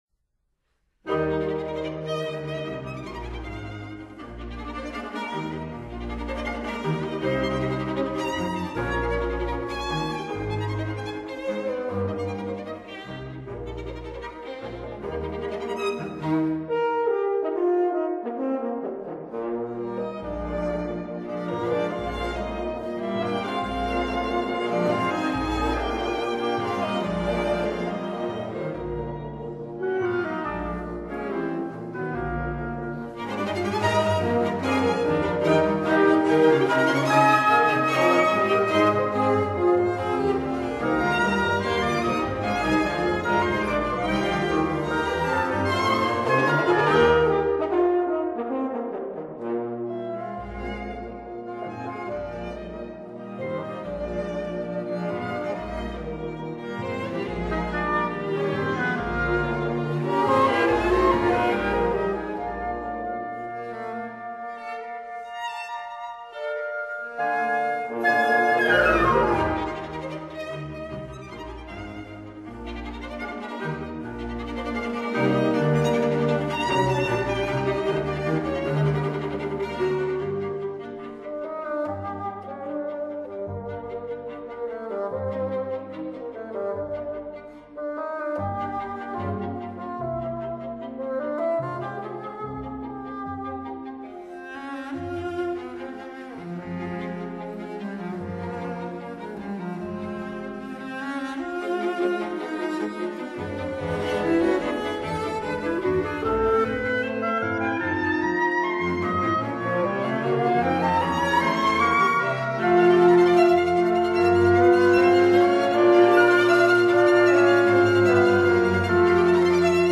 flute
oboe
clarinet
horn
bassoon
violin
viola
cello
double bass